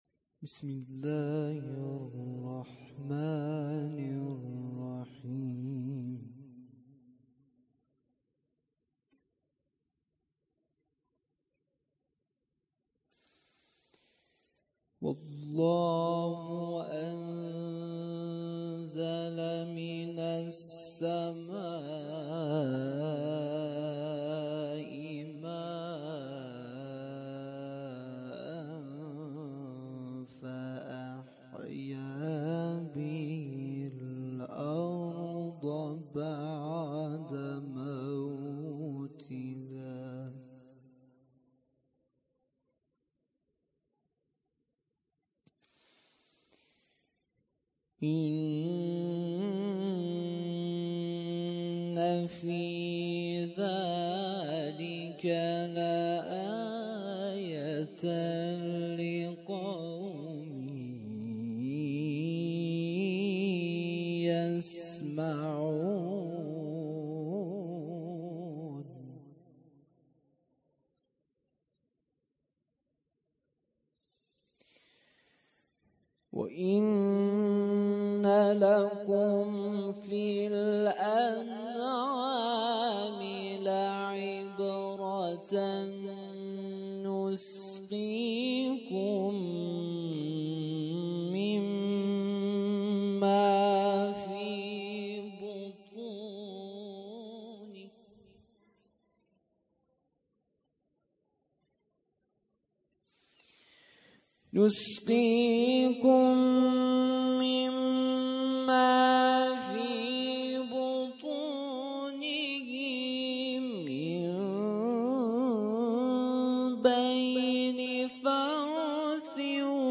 قرائت قرآن - شب دوازدهم محرم ۱۴۰۱